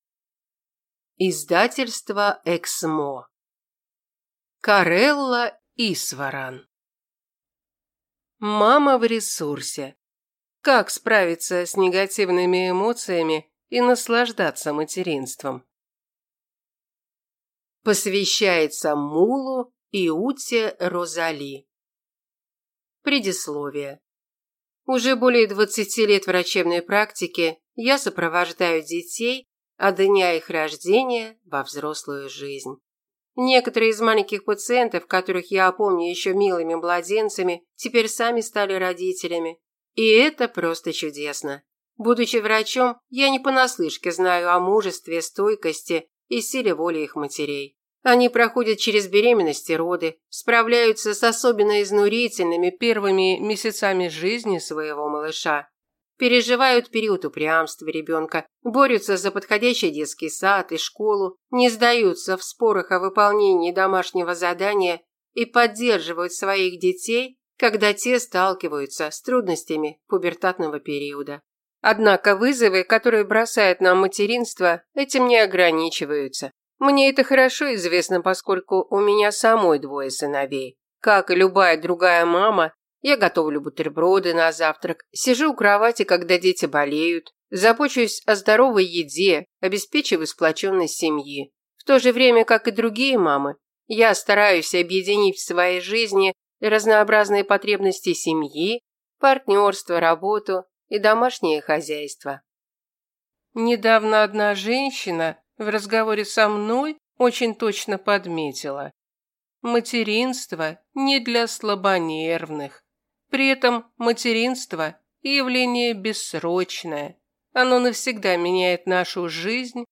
Аудиокнига Мама в ресурсе. Как справиться с негативными эмоциями и наслаждаться материнством | Библиотека аудиокниг